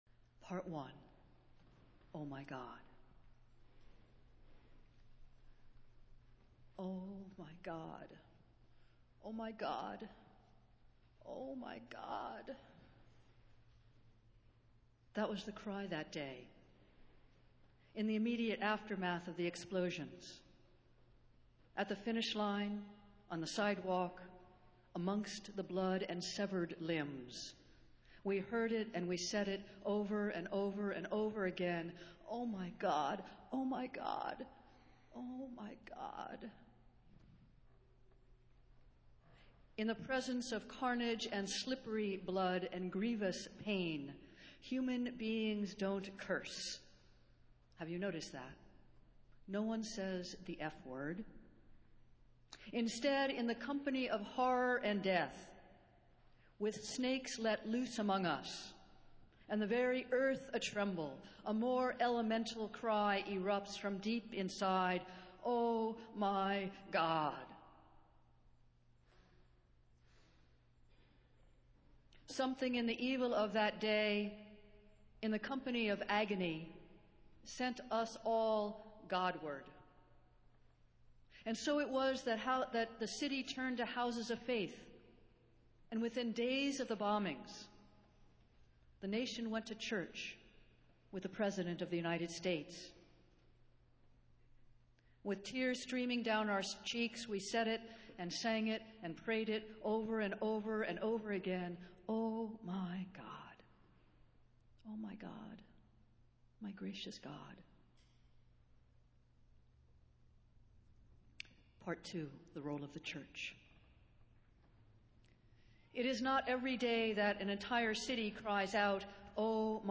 Festival Worship - Six Months Later